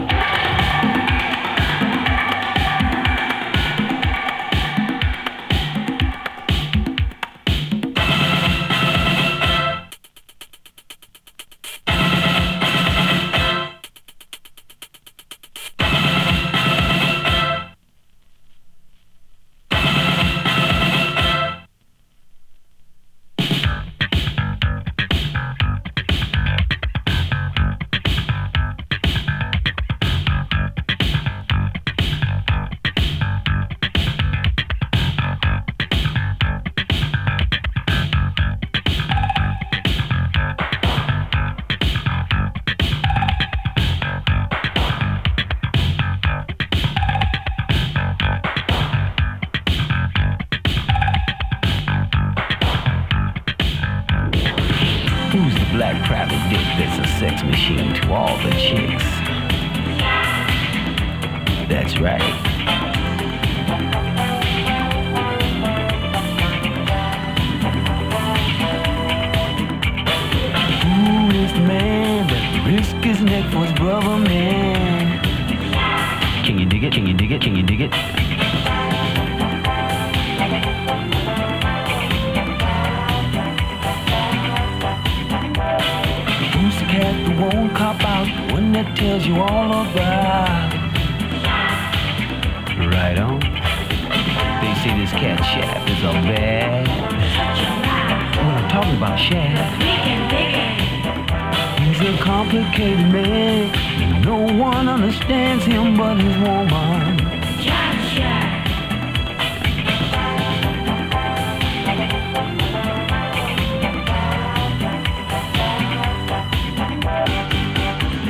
ディスコ カバー
[45RPM 2version+1track 12inch]＊音の薄い部分で軽いチリパチ・ノイズ。